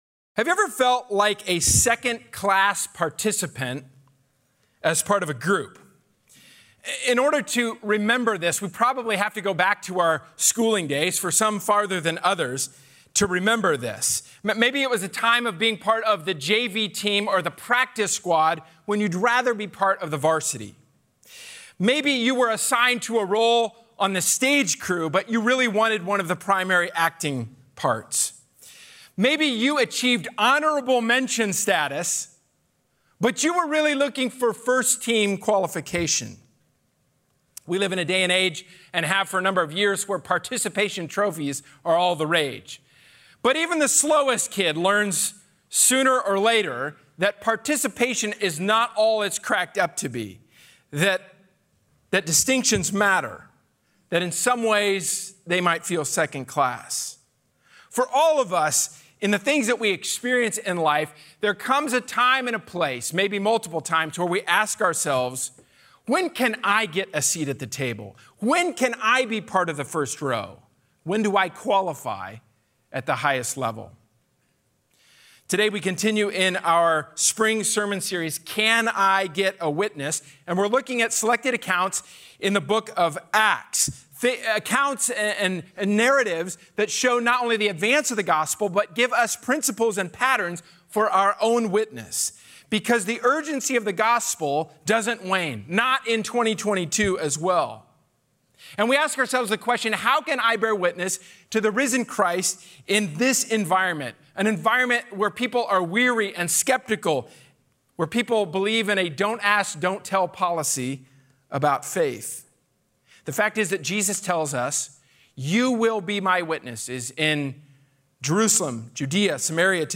A sermon from the series "Can I Get a Witness." God empowers His witnesses in the most difficult circumstances.